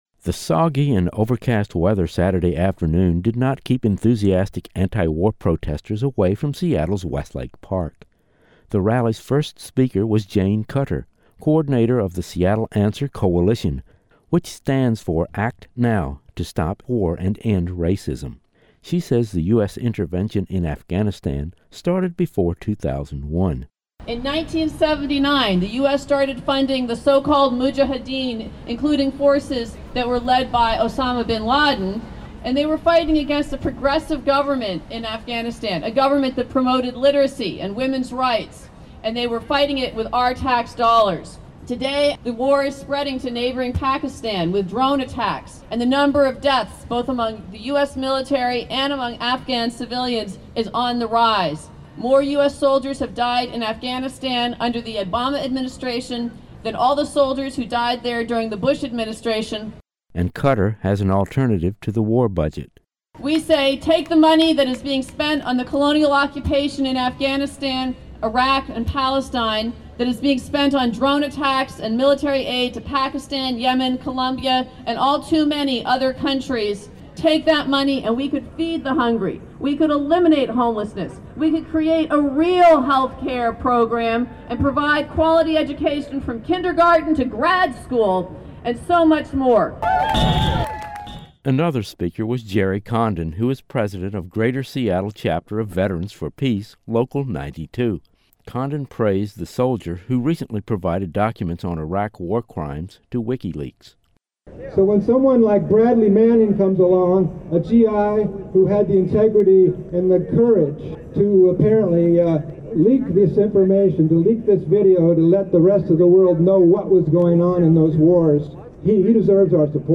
As the U.S. war in Afghanistan entered its tenth year, more than two hundred demonstrators from several anti-war groups gathered in downtown Seattle this past weekend. The rally featured a dozen speakers plus the Seattle Labor Chorus, and was followed by a march to Pioneer Square and back.
Community Radio KBCS has this story from the scene.